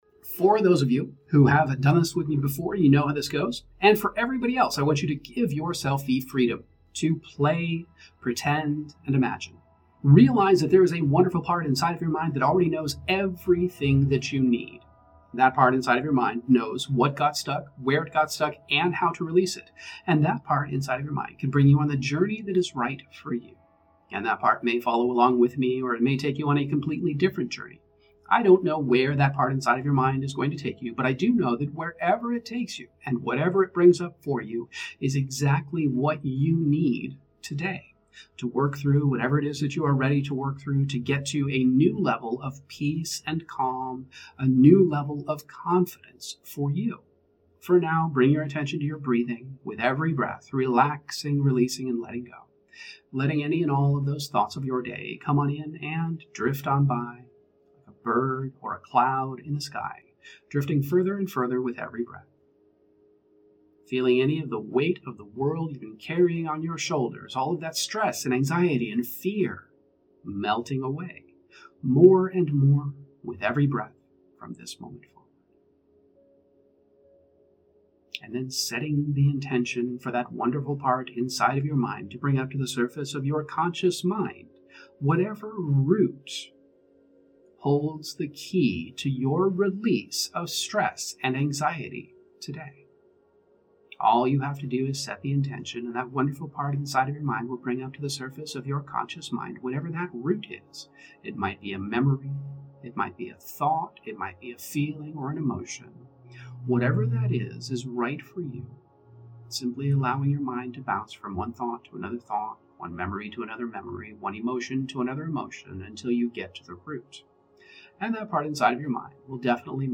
Emotional Optimization™ Meditations